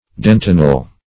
dentinal - definition of dentinal - synonyms, pronunciation, spelling from Free Dictionary
dentinal - definition of dentinal - synonyms, pronunciation, spelling from Free Dictionary Search Result for " dentinal" : The Collaborative International Dictionary of English v.0.48: Dentinal \Den"ti*nal\, a. (Anat.)